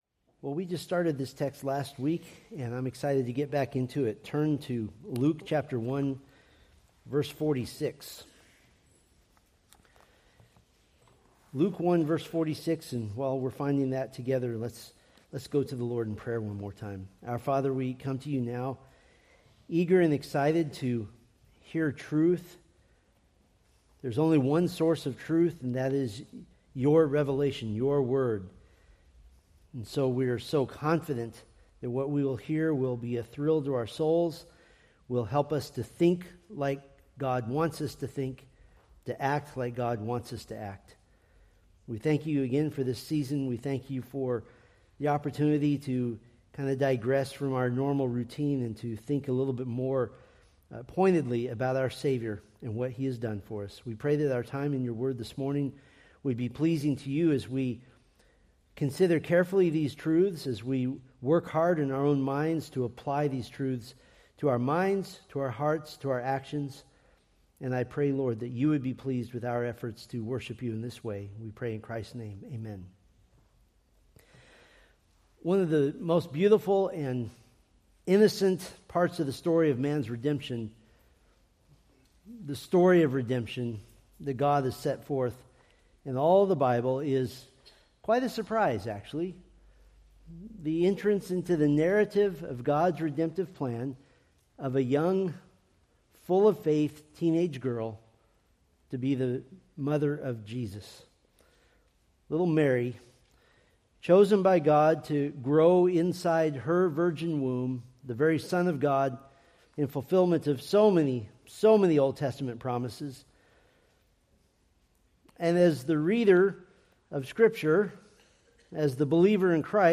From the Mary's Christmas sermon series.
Sermon Details